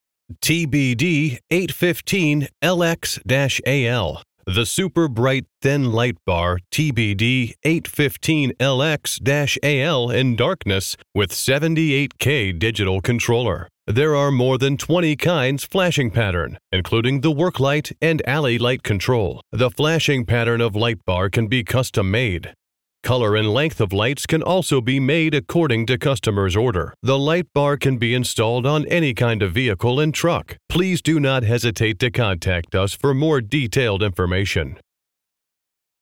Warning Led Lightbar Chinese manufacturer sound effects free download
Police/Ambulance/Tow/FireTruck